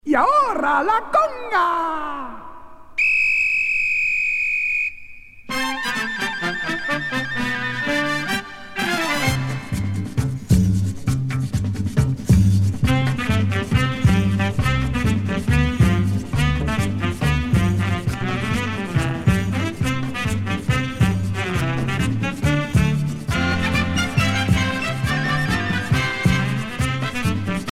danse : conga